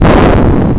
sfx_expl_ship.wav